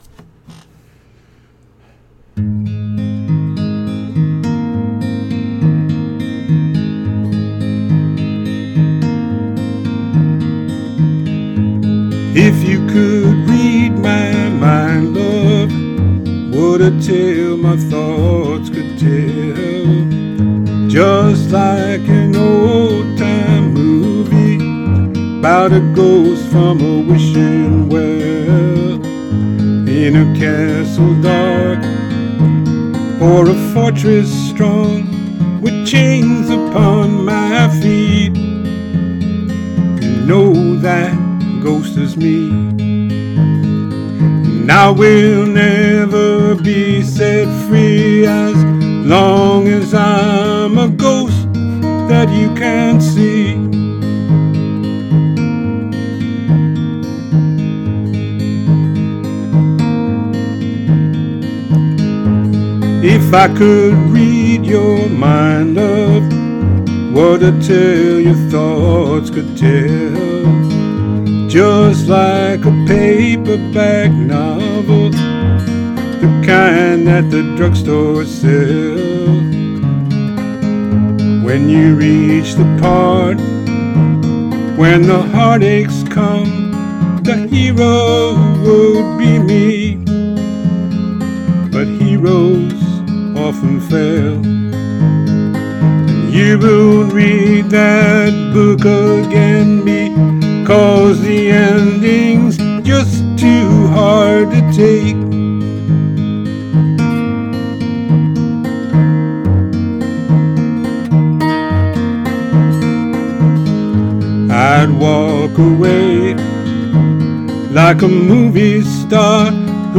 Here’s my cover of the song.